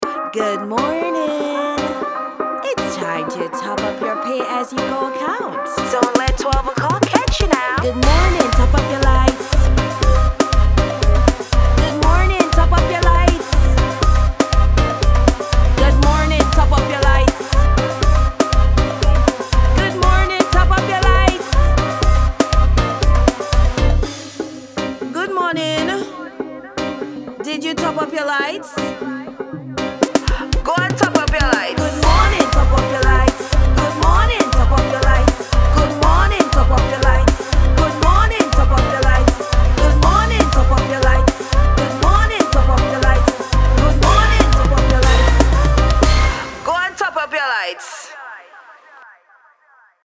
Alarm Ringtone
domlec-paug-ringtone-march-2026.mp3